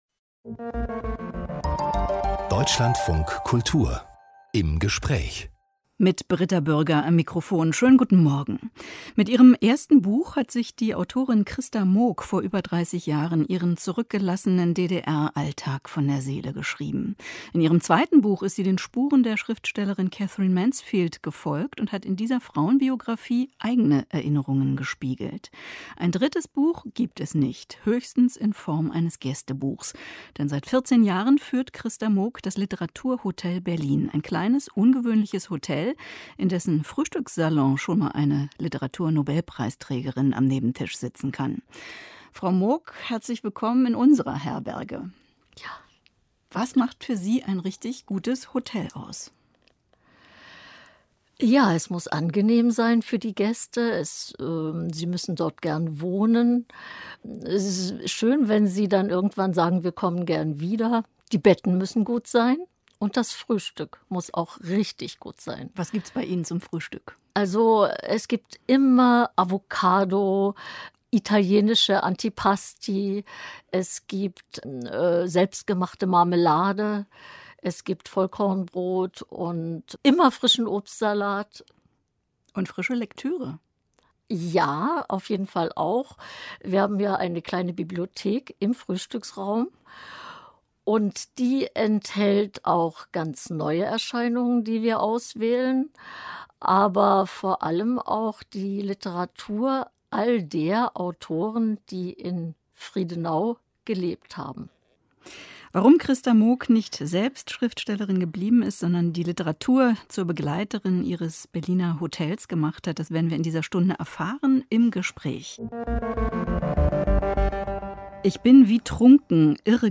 bei uns zu Gast am 01.09.2017 09:07 Uhr